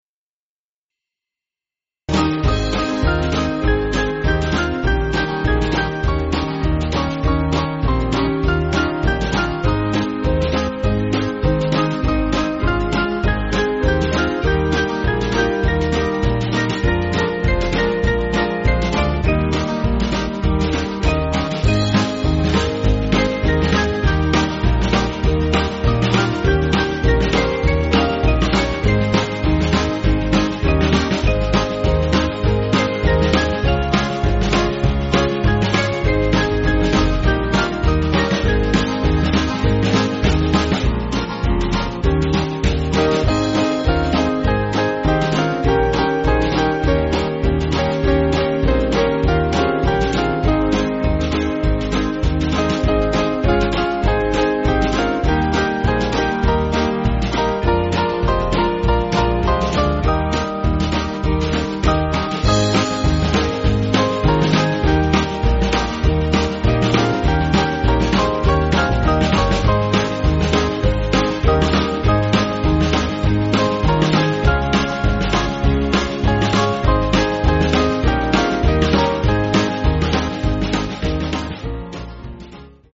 Small Band
(CM)   3/Ab